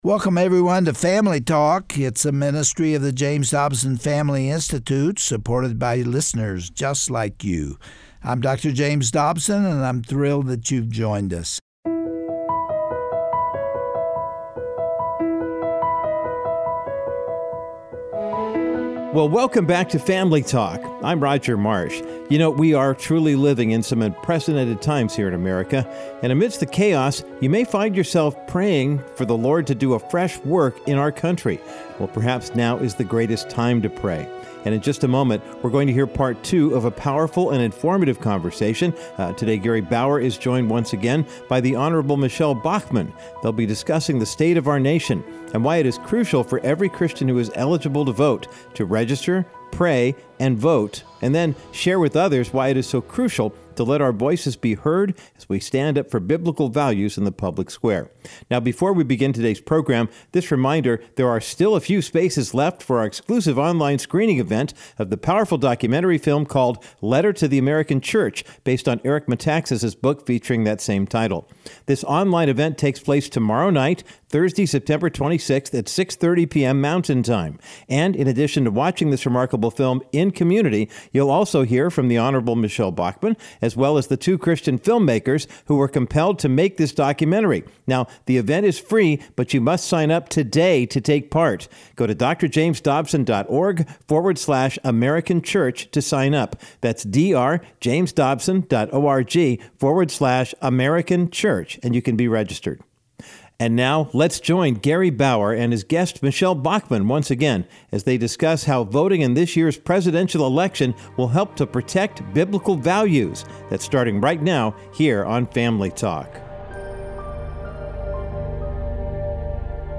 On today’s edition of Family Talk, Gary Bauer concludes his critical discussion with the Hon. Michele Bachmann about the necessity of having a biblical basis on which to determine the answer to the question, “How should I vote?”
Host Gary Bauer